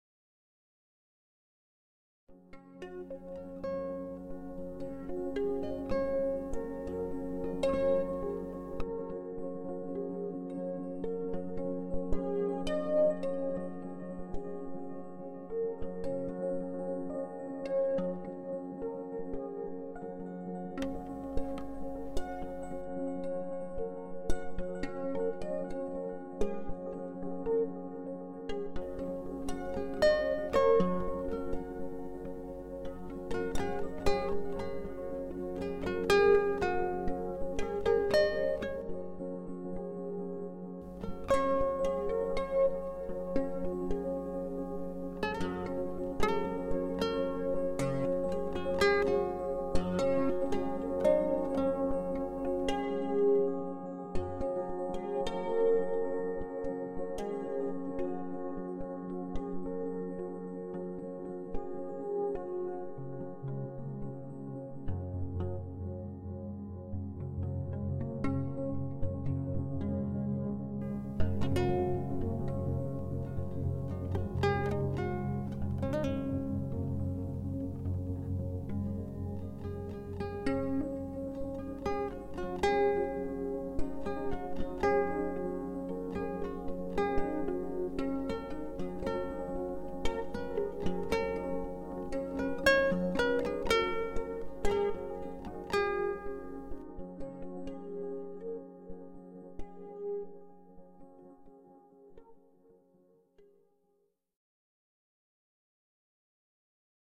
Musica a 432 Hz